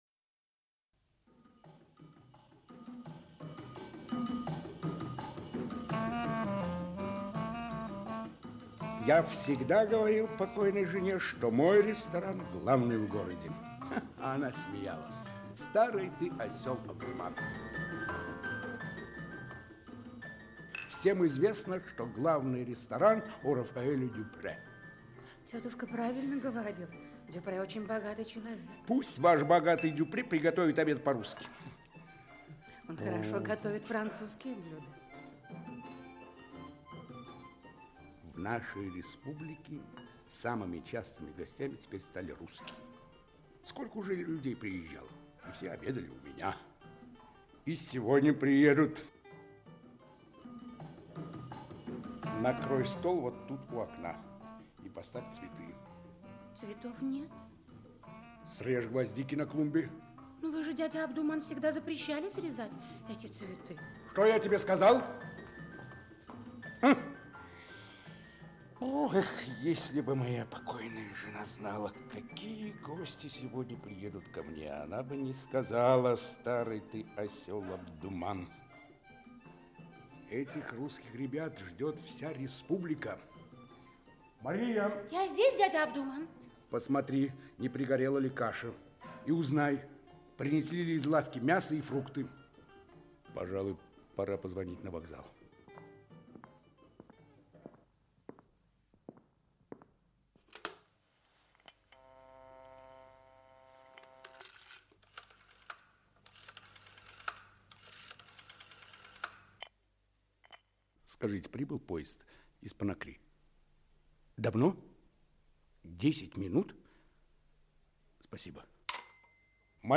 Радиопостановка в исполнении артистов Ленинградского государственного академического Большого драматического театра им. М. Горького.